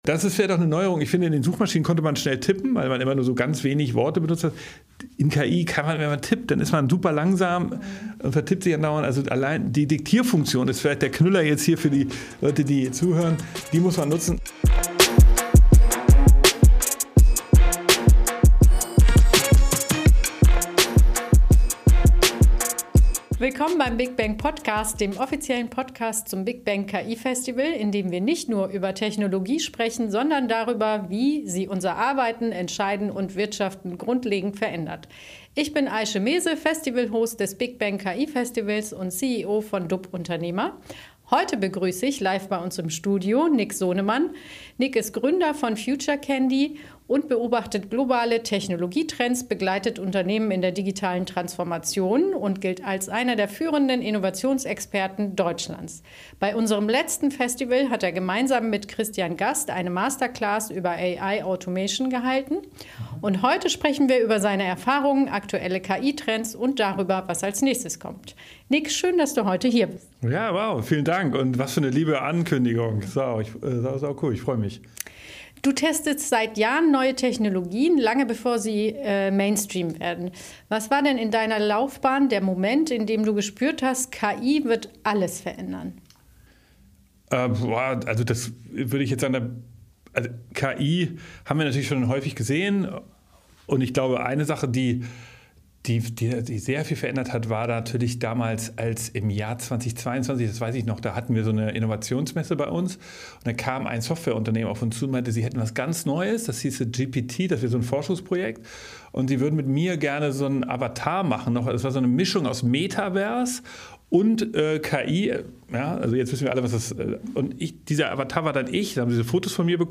Im Gespräch wird deutlich: Das zentrale Problem ist nicht die Technologie, sondern der Umgang mit ihr. Viele Unternehmen warten auf die „richtige Lösung“, statt systematisch zu testen.